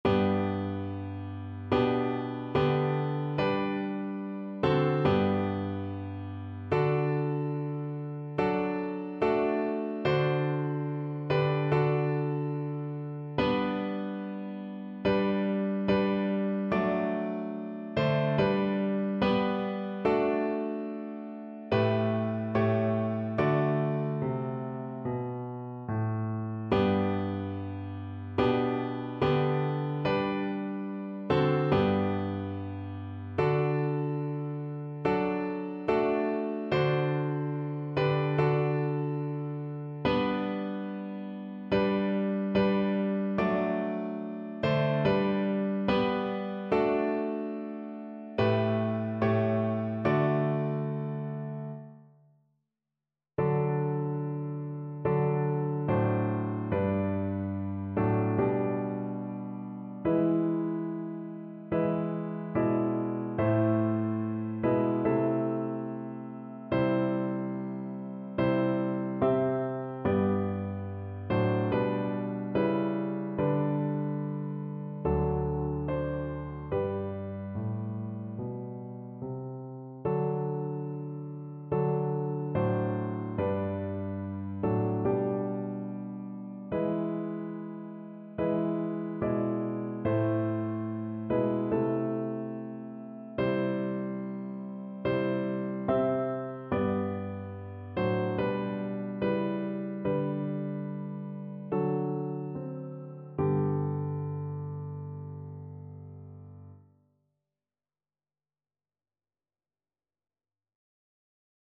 (Latvian National Anthem) Piano version
No parts available for this pieces as it is for solo piano.
G major (Sounding Pitch) (View more G major Music for Piano )
Maestoso =c.72
4/4 (View more 4/4 Music)
Piano  (View more Intermediate Piano Music)
Classical (View more Classical Piano Music)